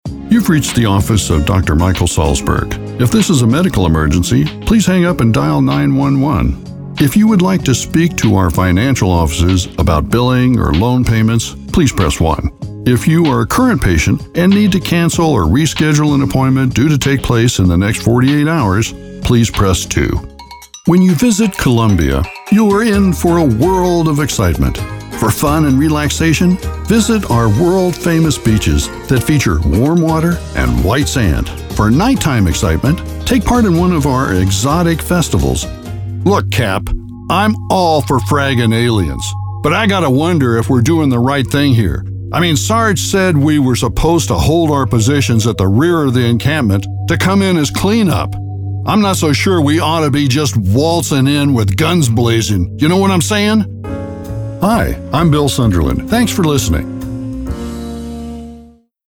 My age range is 50 to 75 years, US-English.
Narrative Demo Click Here!